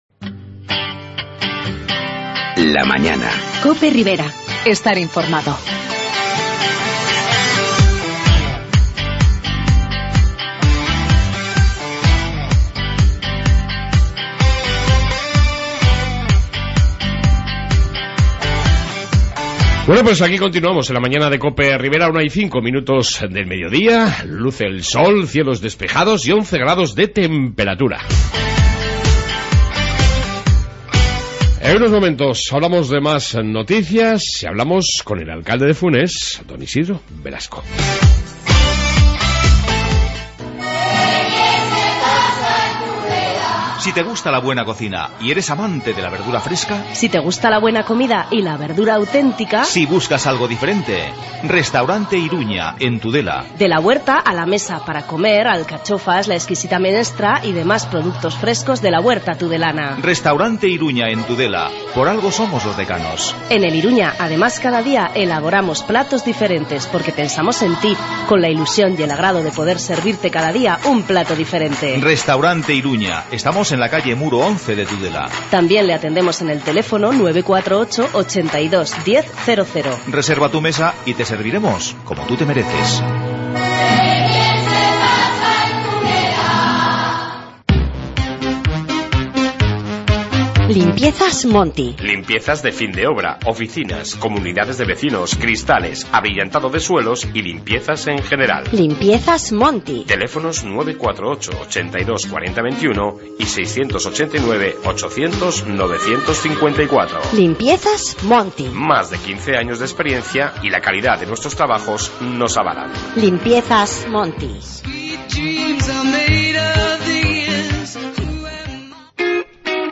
AUDIO: Entrevista con el Alcalde de Funes Isidro Velasco y mas noticias de Interés...